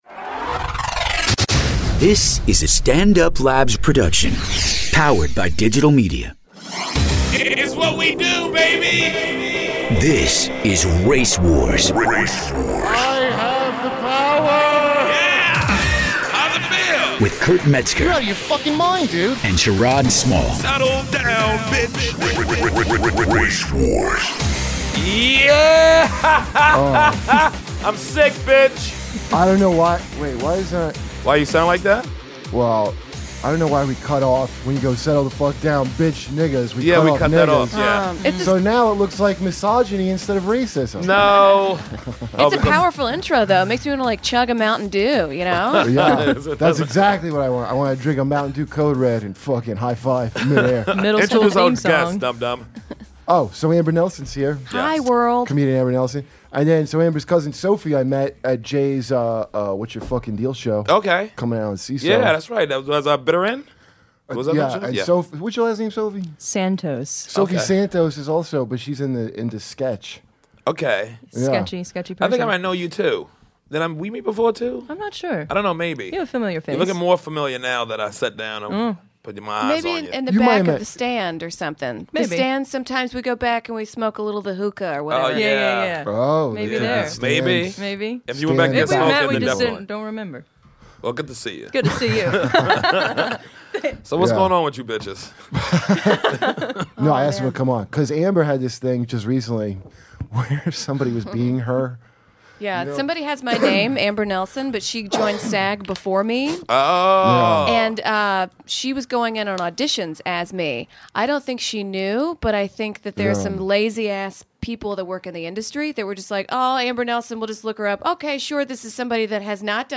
in studio this week